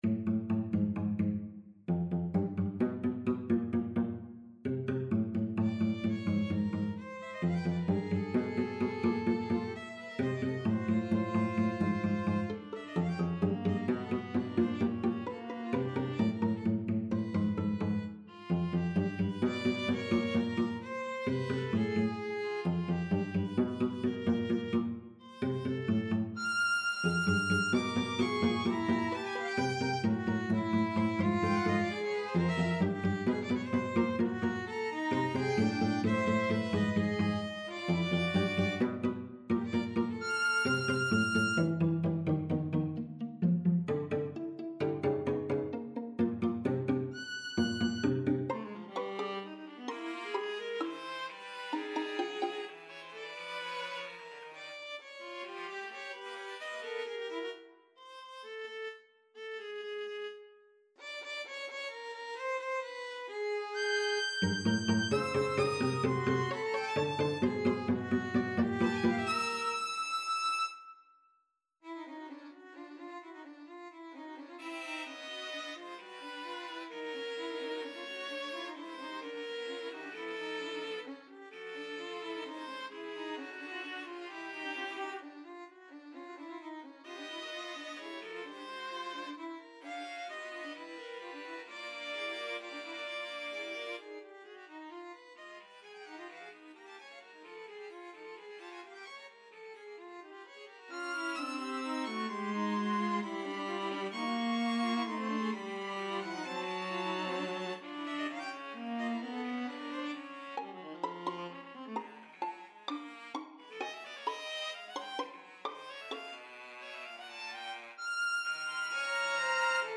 The following are are some of my compositions realized in midi.
Vigil for Max, an atonal string quartet inspired by Latin American rhythms: